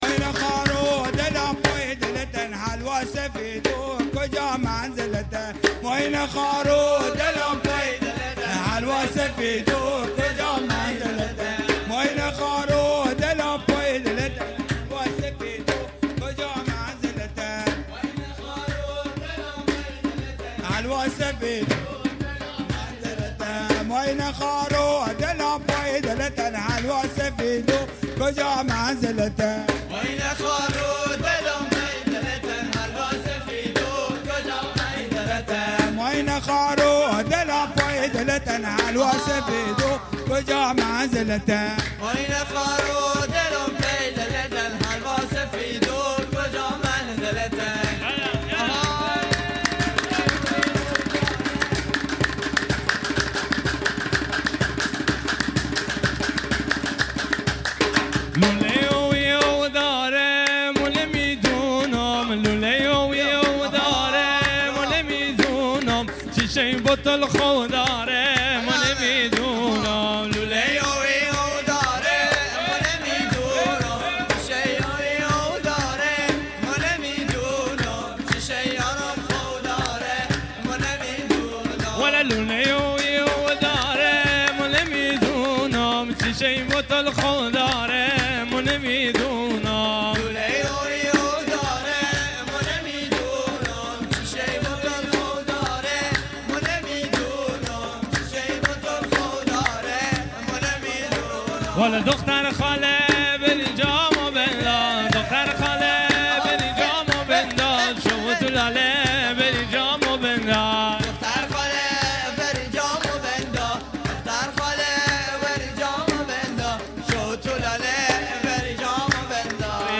بندری